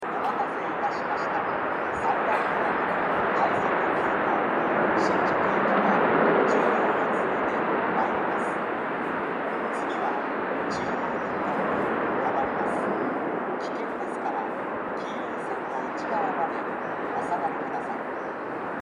この駅では接近放送が設置されています。
３番ホームOE：小田急江ノ島線
接近放送快速急行　新宿行き接近放送です。